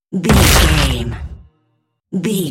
Dramatic deep hit bloody
Sound Effects
Atonal
heavy
intense
dark
aggressive
hits